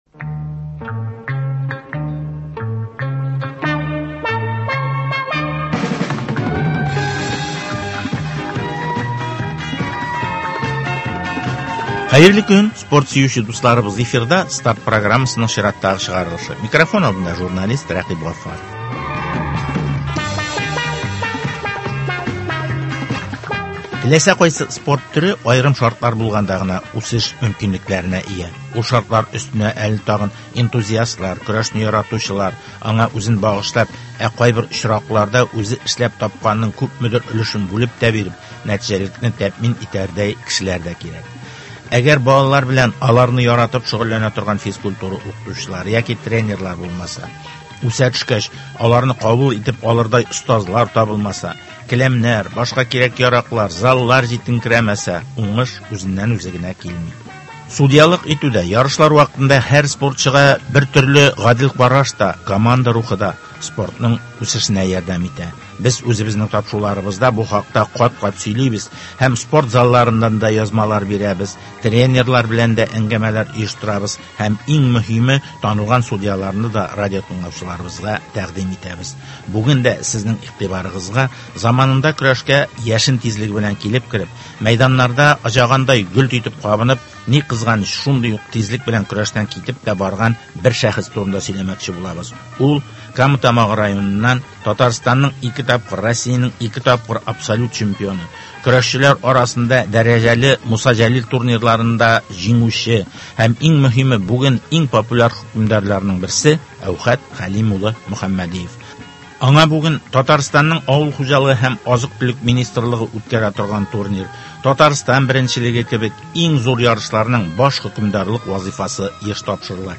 Казан – Россиянең спорт башкаласы, авылда спортның үсеше, дөнья күләмендәге чемпионатларга әзерләнү, районнар масштабындагы ярышларны үткәрү – әлеге һәм башка темалар хакында спортчылар, җәмәгать эшлеклеләре һәм спорт өлкәсендәге белгечләр белән әңгәмәләр.